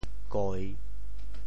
“计”字用潮州话怎么说？
潮州 goi3 白 对应普通话: jì